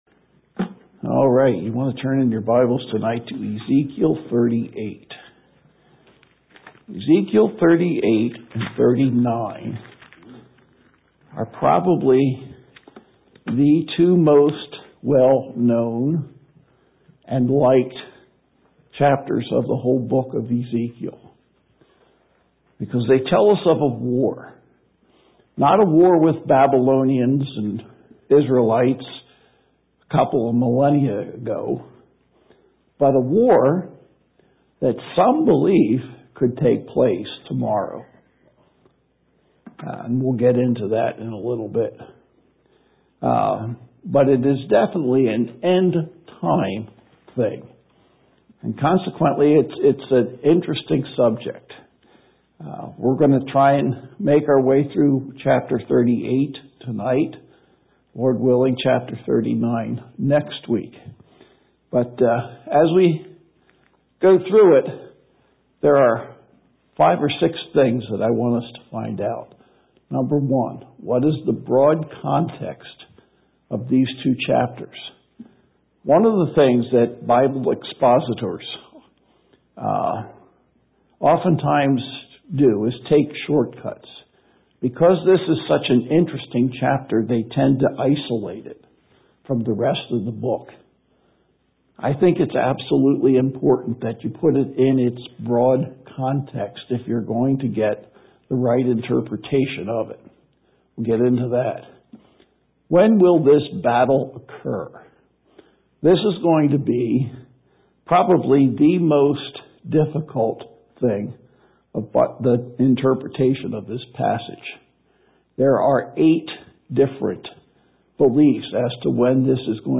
Worship Messages